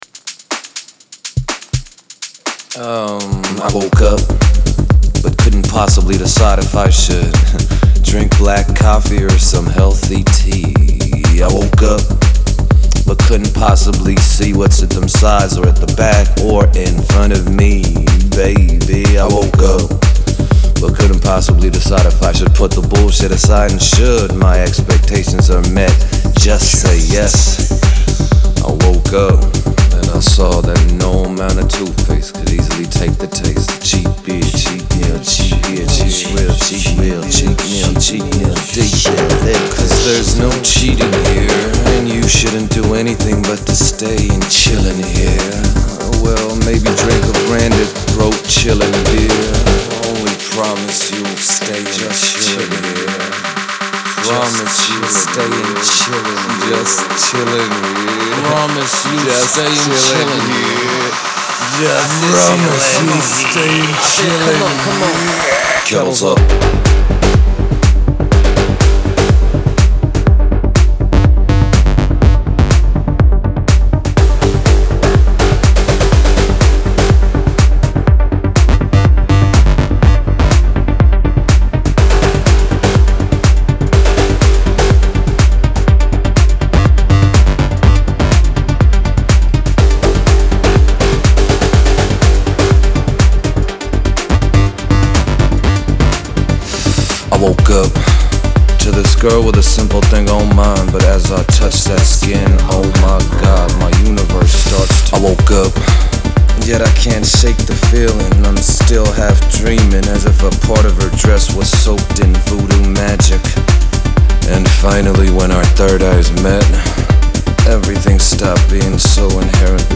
• Жанр: Electronic, Dance